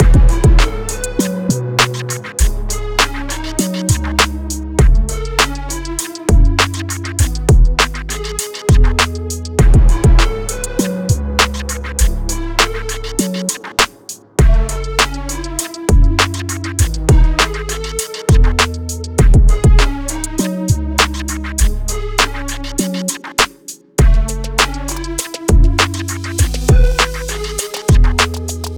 Chill
Verb Distorted
Db Minor